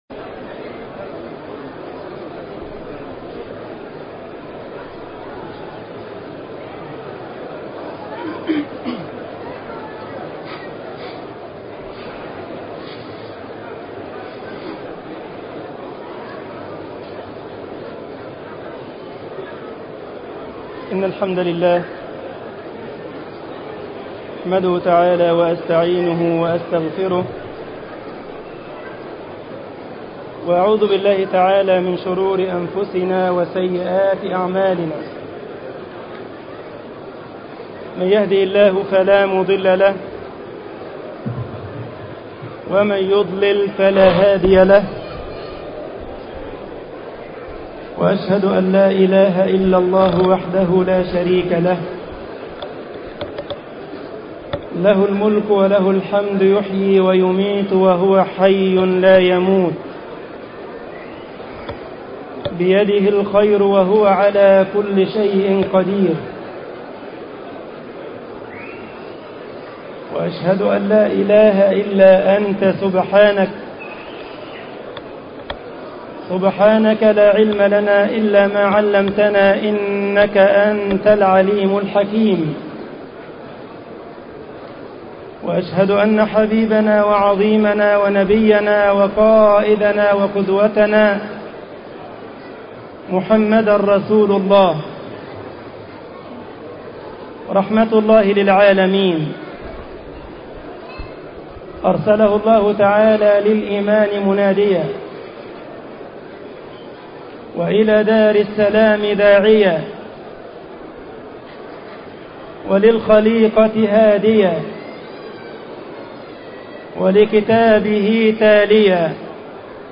خطبة عيد الفطر
مسجد الجمعية الإسلامية بالسارلند ـ ألمانيا